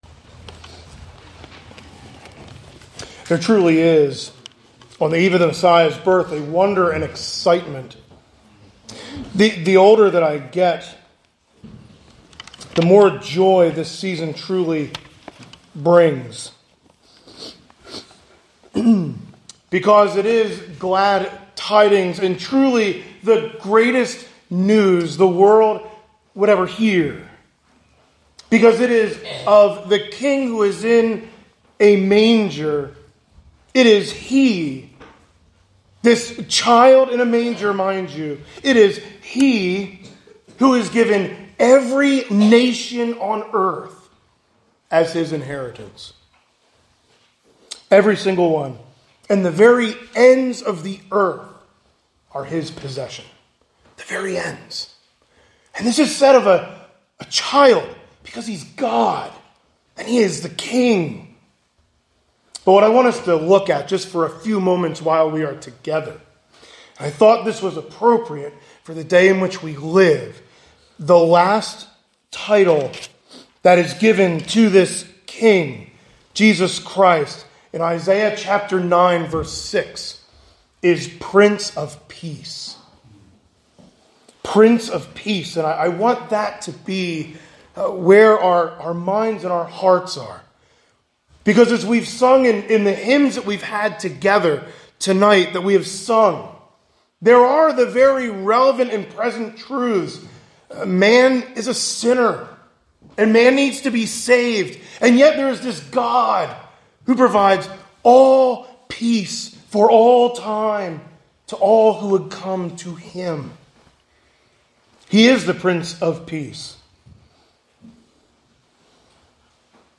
2024 Christmas Eve sermon.